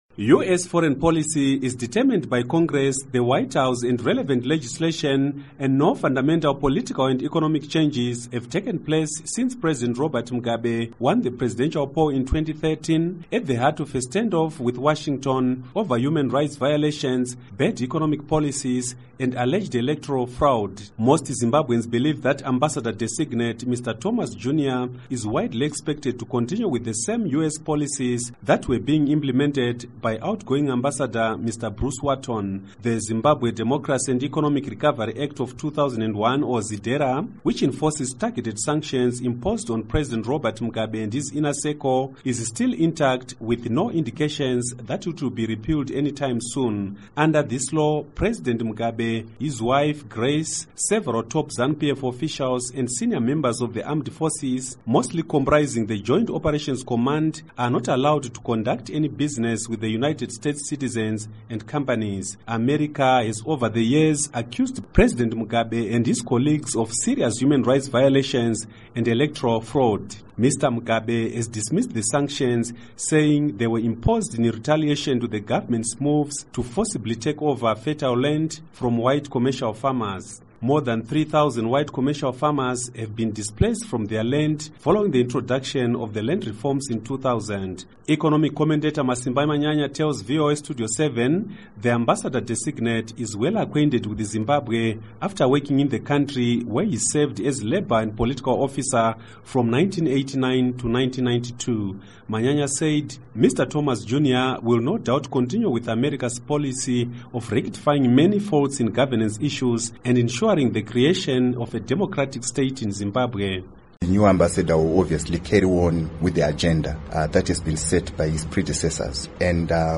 Report on U.S Envoy